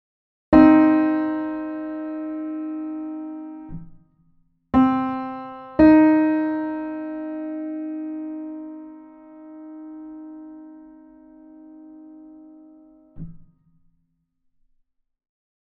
De afstand van een C naar een E♭ bestaat uit drie halve noten, heet een kleine terts en ervaren wij als triest:
sad-3.mp3